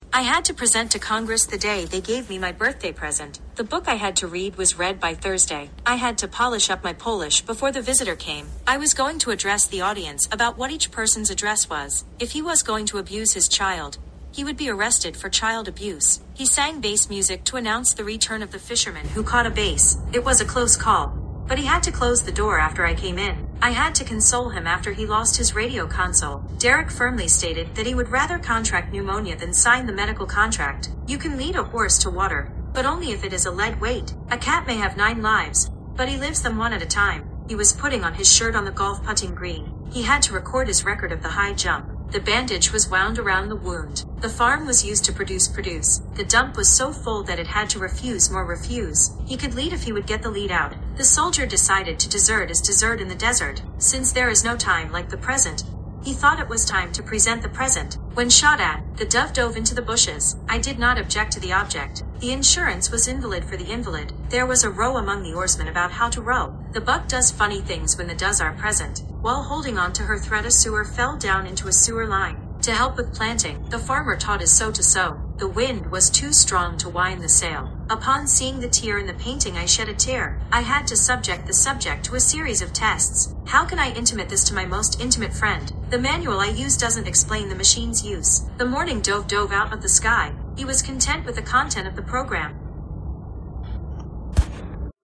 I’ve tried that a few times, but the computer lacks the feeling of the work, so there is usually no emphasis, where needed, in the piece.
Here is a recording of my iPad reading all of the sentences I wrote.
Heteronyms-.mp3